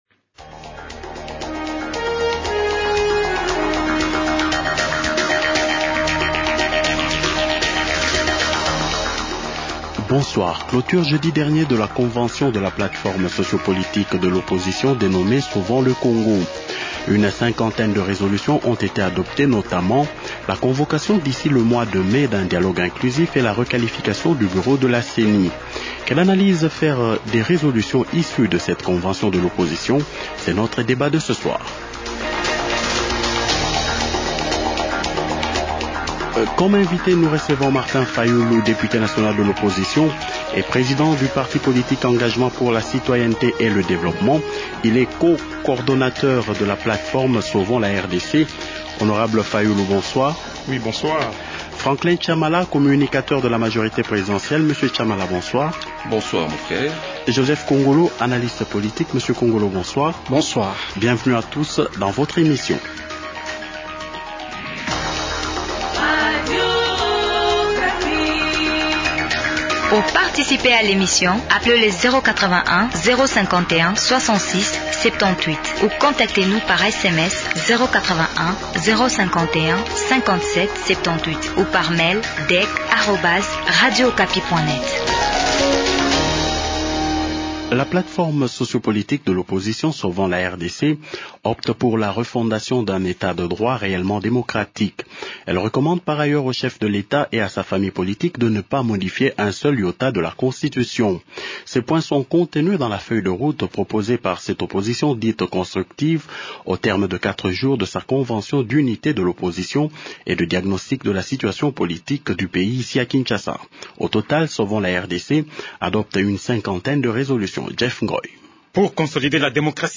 Invités: Martin Fayulu, Député national de l’opposition et président du parti politique Engagement pour la citoyenneté et le développement.